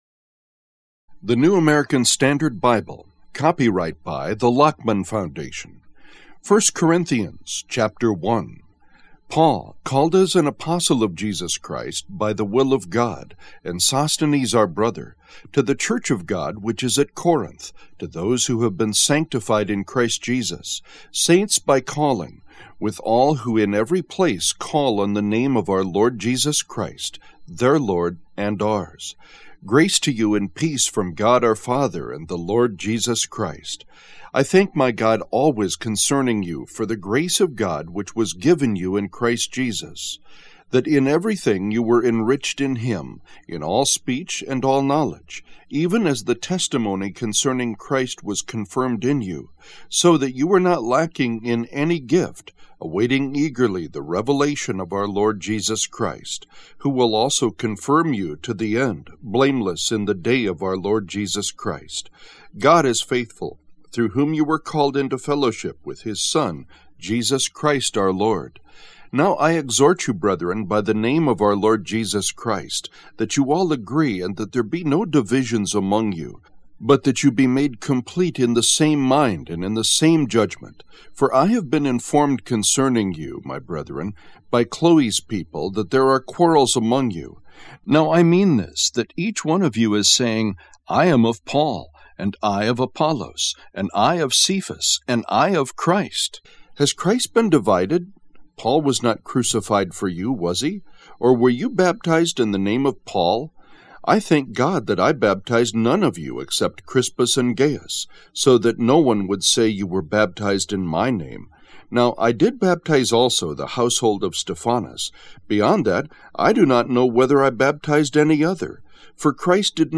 The Book of 1st Corinthians Audiobook
0.9 Hrs. – Unabridged